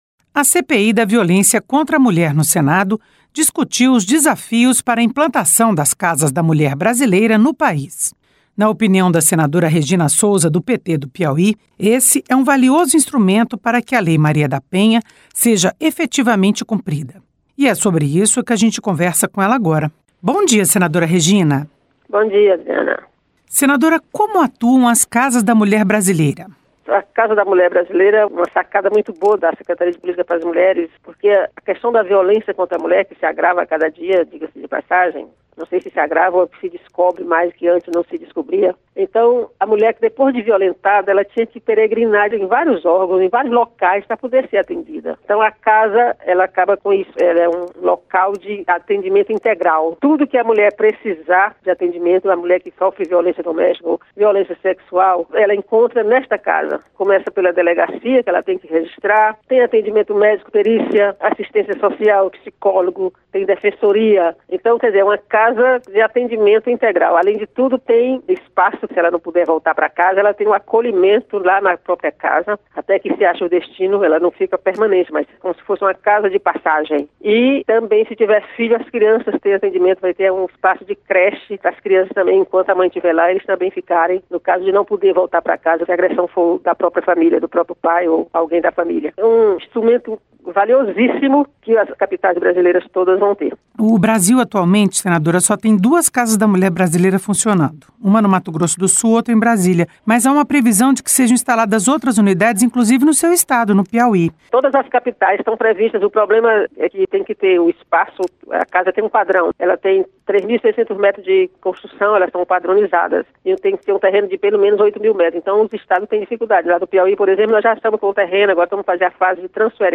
Entrevista com a senadora Regina Sousa (PT-PI).